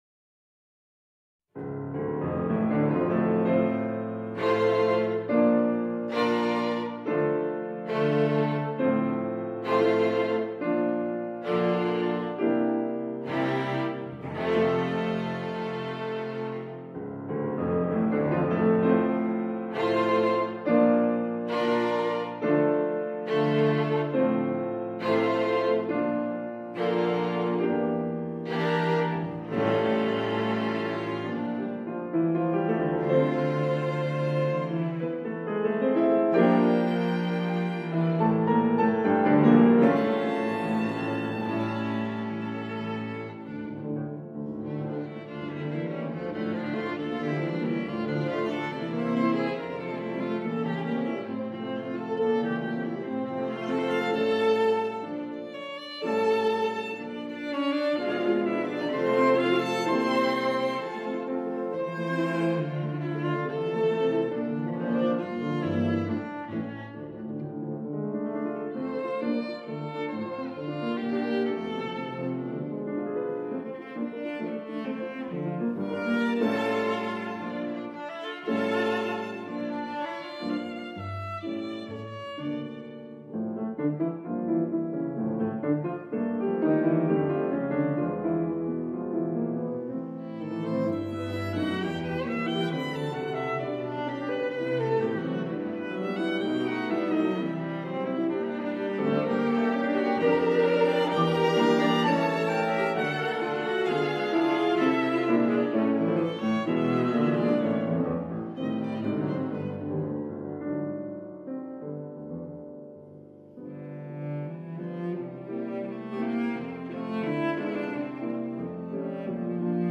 The work begins with an Andante Maestoso introductions characterized by repeated chords in the strings against an ascending piano part. The main section is full of charm, quite romantic, lyrical but not overly passionate.